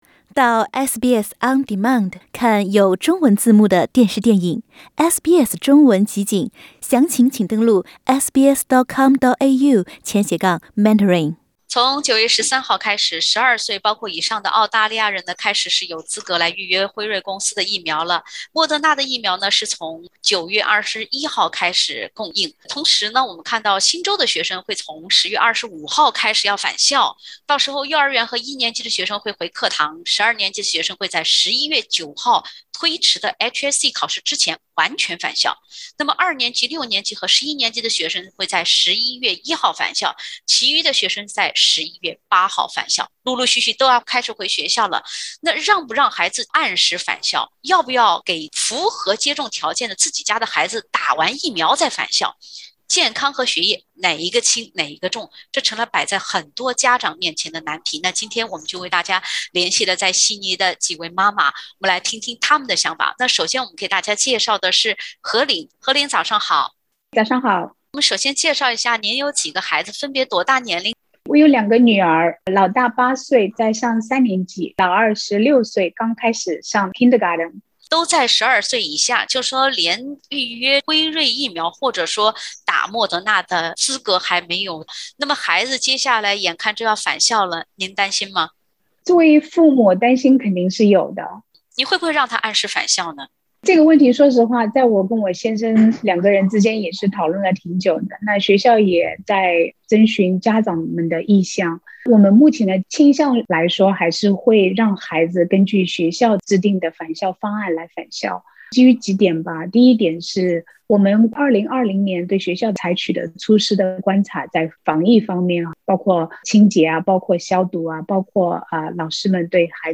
新州学生将从10月25日开始陆续返校，悉尼四位学龄儿童的母亲，有考虑暂时不让孩子按时返校，也有在政府刚批准为12岁及以上孩子接种疫苗的当天，就为刚满12岁孩子接种疫苗，为返校做准备的。（点击封面图片，收听完整采访）